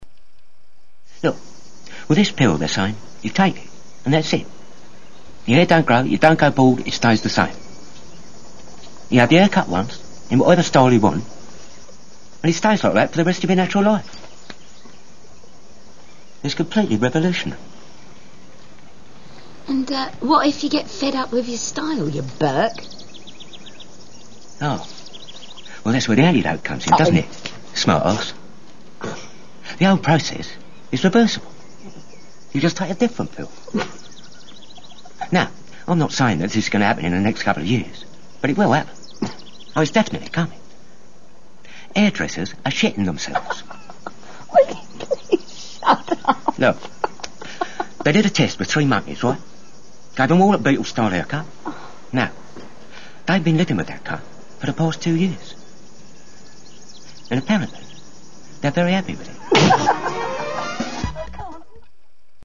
Aside from Warren Beatty and the luminous Julie Cristie in “Shampoo”, it made me remember this wonderful piece of dialogue on that very subject from one of my favorite films.